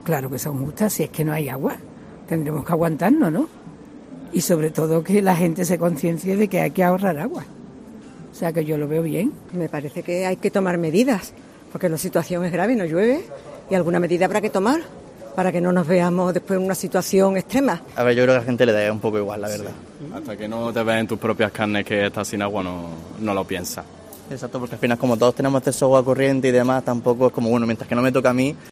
Así lo han comentado en los micrófonos de COPE Sevilla, asegurando que es muy necesario “hacer algo” porque “no hay agua”, por lo que “tendremos que aguantarnos”.